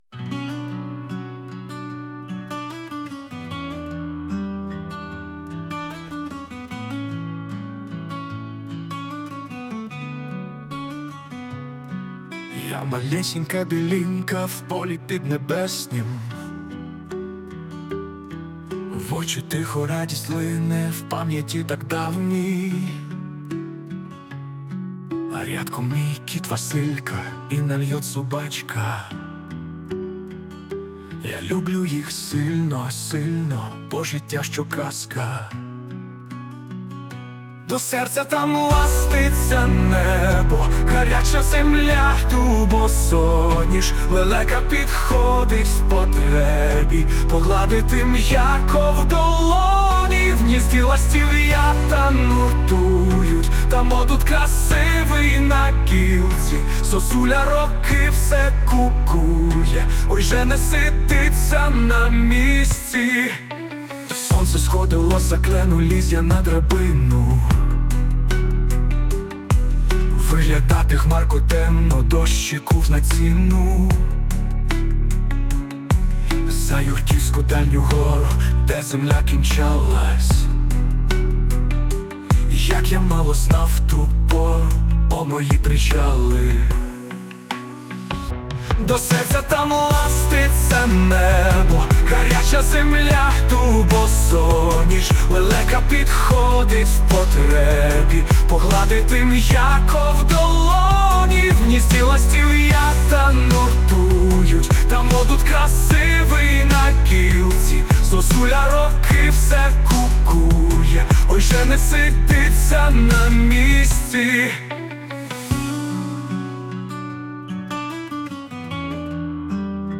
Мелодія на слова пісні: